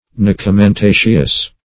Nucamentaceous synonyms, pronunciation, spelling and more from Free Dictionary.